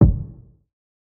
CDK Closet Kick.wav